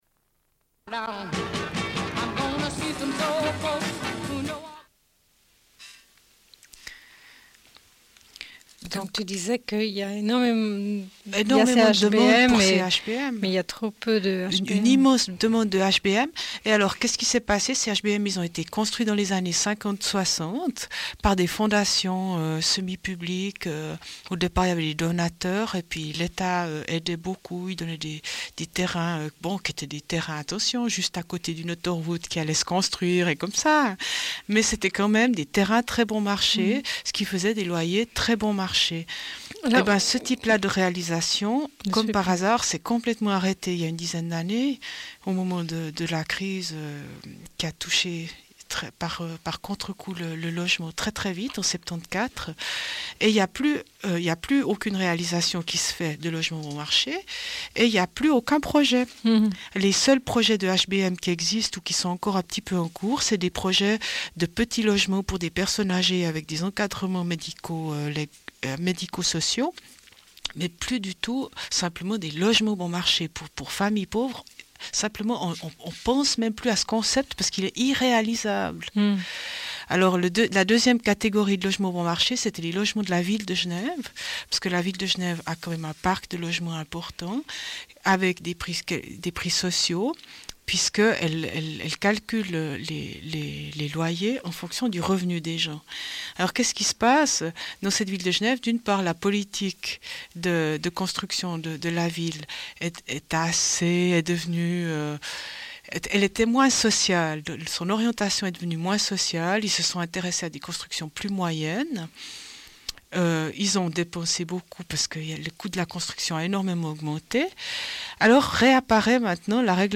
Une cassette audio, face A31:41